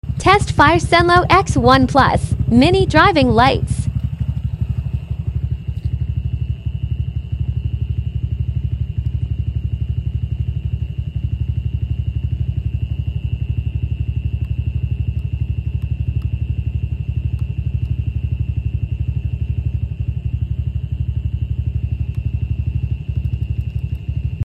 Test Fire Senlo X1 Plus Sound Effects Free Download